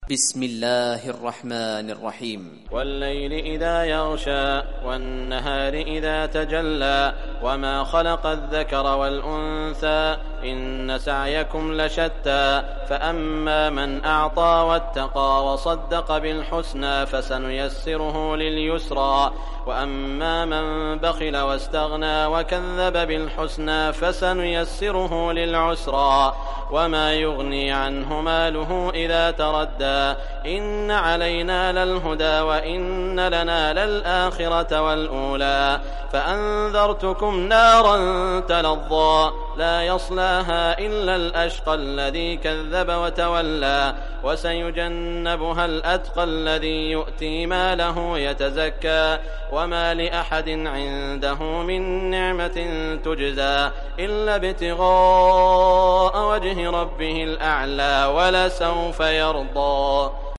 Surah Lail Recitation by Sheikh Saud Shuraim
Surah al Lail, listen or play online mp3 tilawat / recitation in Arabic in the beautiful voice of Sheikh Saud al Shuraim.